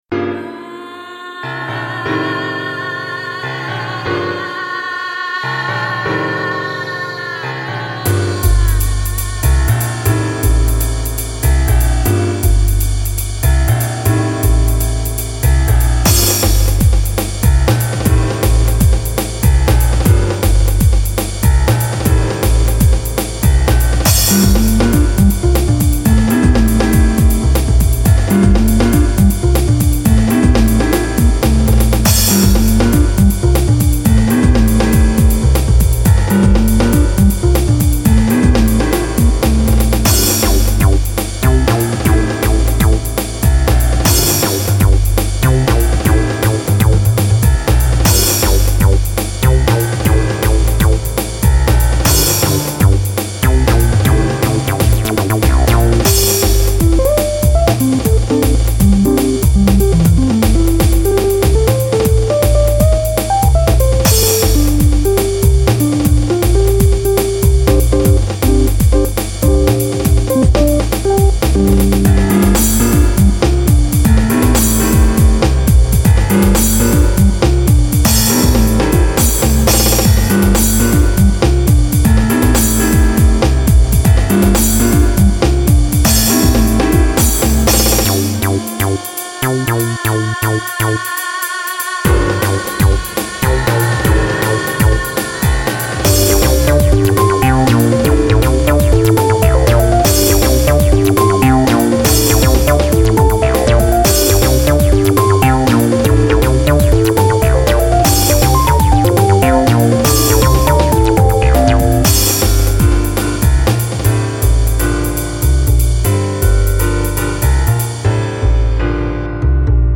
You need mda Piano free vst.
Genre Acid Jazz